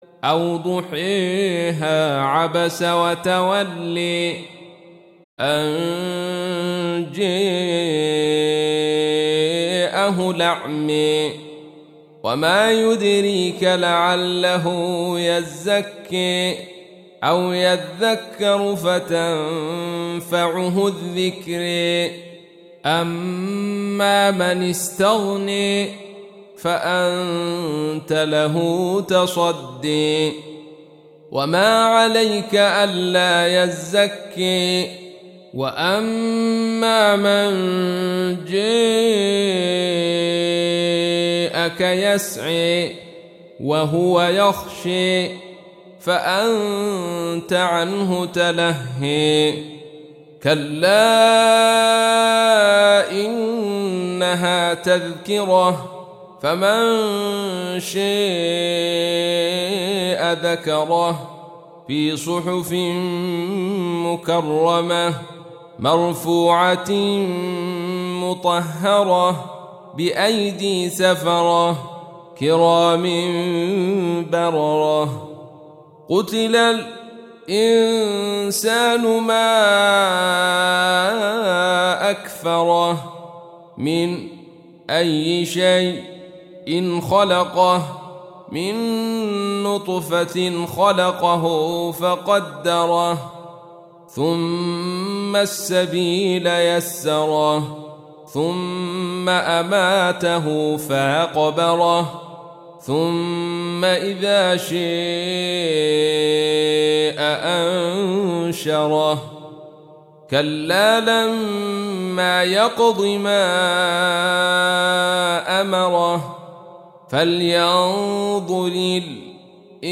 Surah Sequence تتابع السورة Download Surah حمّل السورة Reciting Murattalah Audio for 80. Surah 'Abasa سورة عبس N.B *Surah Includes Al-Basmalah Reciters Sequents تتابع التلاوات Reciters Repeats تكرار التلاوات